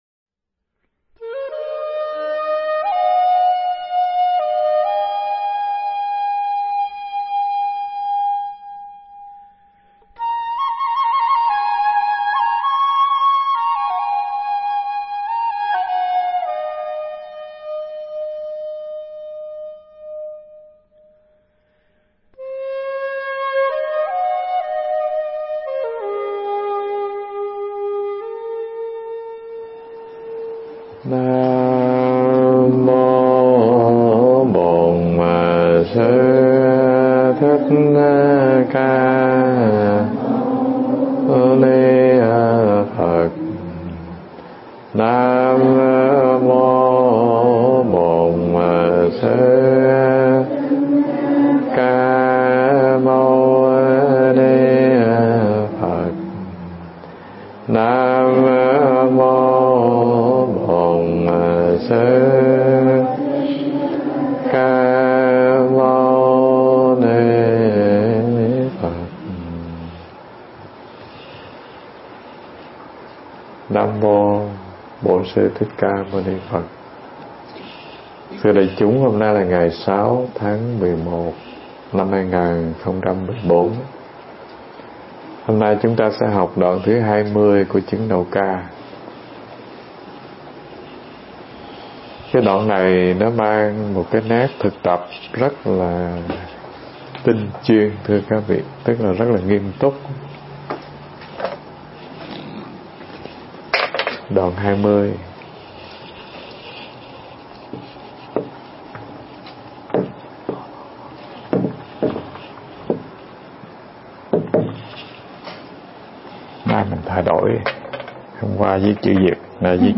Mp3 Thuyết pháp Chứng Đạo Ca 21 An Trú Nơi Tự Tánh